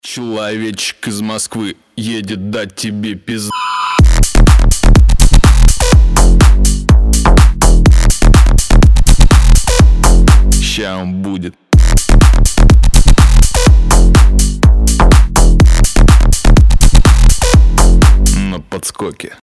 • Качество: 320, Stereo
веселые
мощные басы
Bass House
качающие
смешные